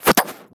arrowsound.mp3